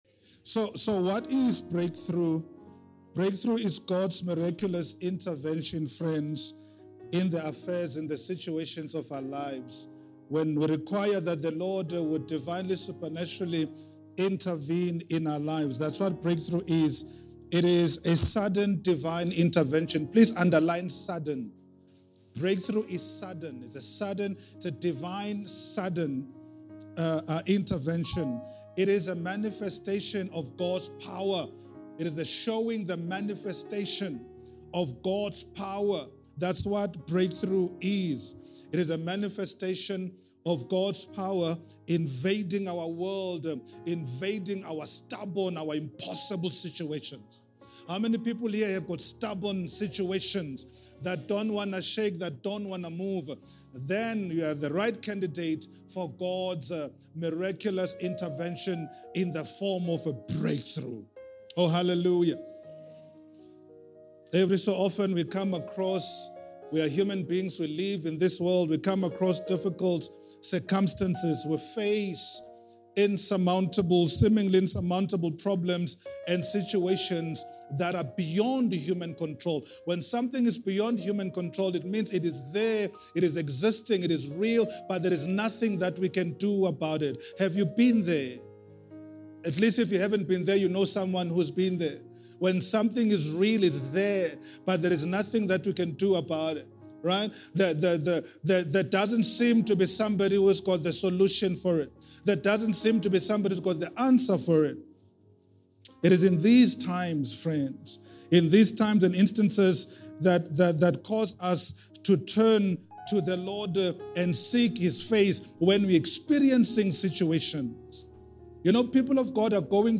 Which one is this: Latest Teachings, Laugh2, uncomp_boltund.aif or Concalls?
Latest Teachings